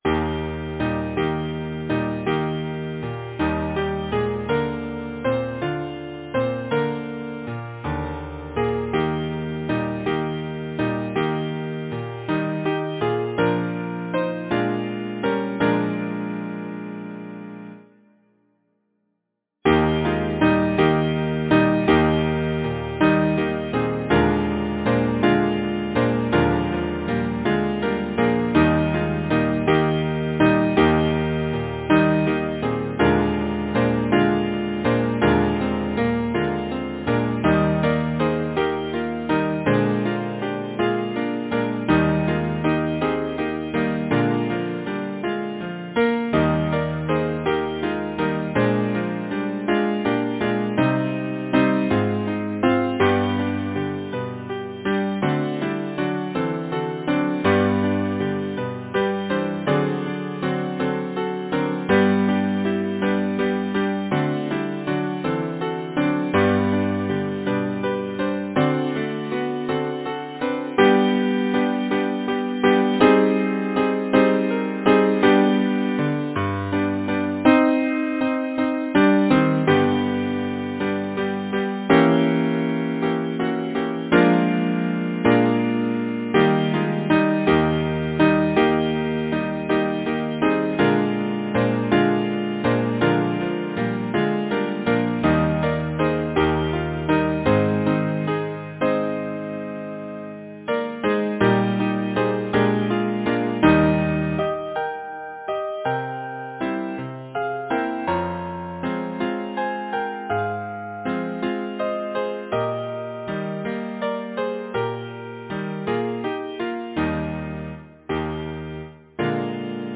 Title: Children Composer: Thomas Willert Beale Lyricist: Henry Wadsworth Longfellow Number of voices: 4vv Voicing: SATB Genre: Secular, Partsong
Language: English Instruments: Piano